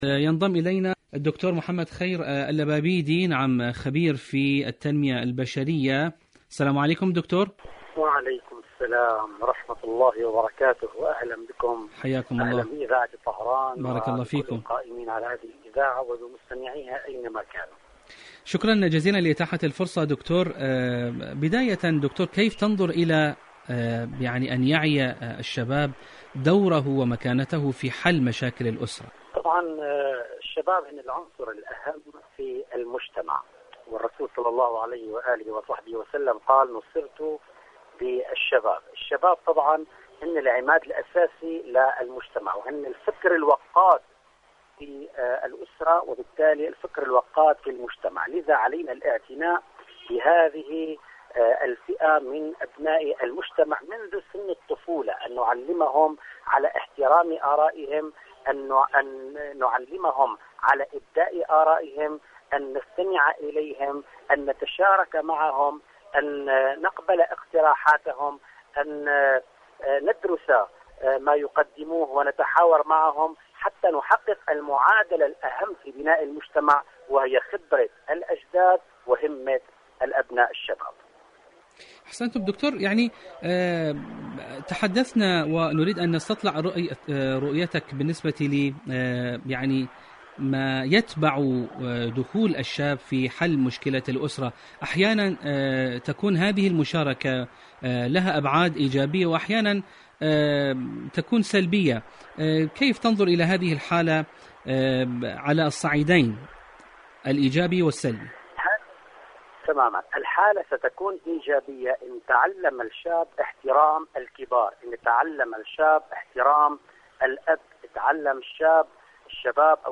مقابلات الشباب المشاكل الأسرية الأسرة مقابلات إذاعية برامج إذاعة طهران برنامج دنيا الشباب دور الشباب في حل مشاكل الأسرة شاركوا هذا الخبر مع أصدقائكم ذات صلة التوجة لإسلامي العام الداعم لإيران والرافض للعدوان عليها..